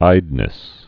(īdnĭs)